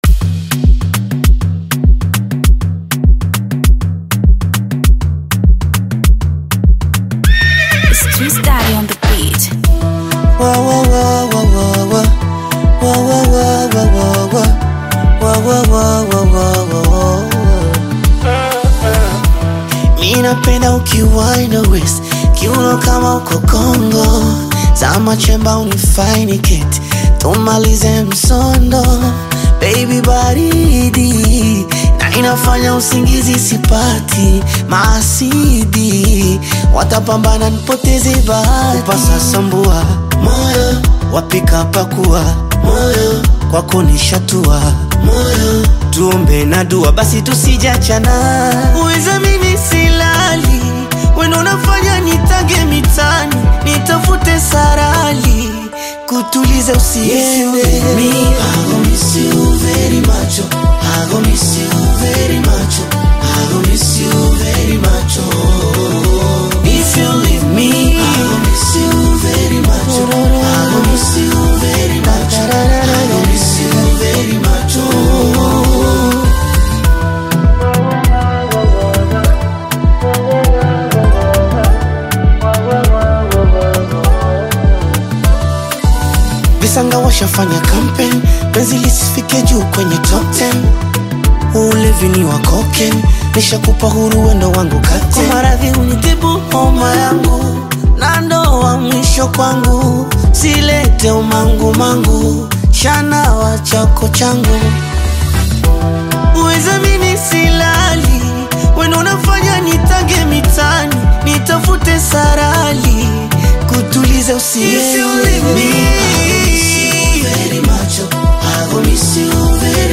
” is a melodious and emotionally charged track.
With his soulful vocals and poignant lyrics